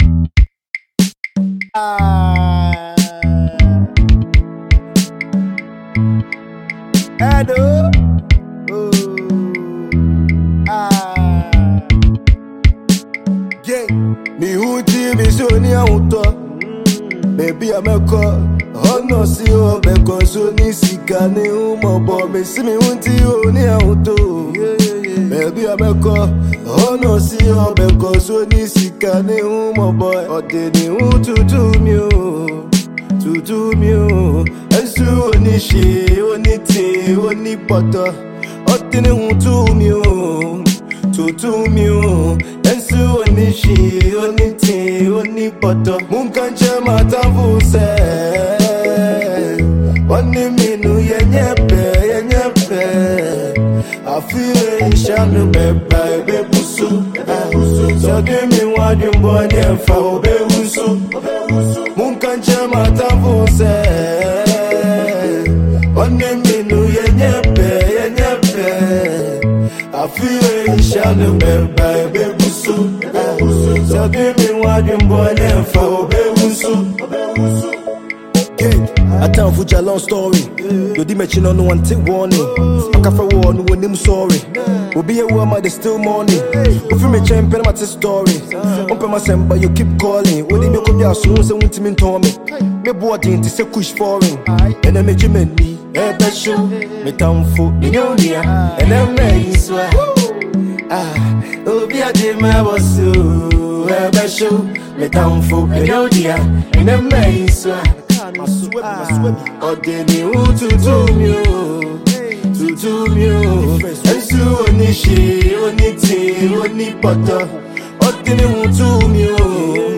GHANA MUSIC
a Ghanaian Musical rapper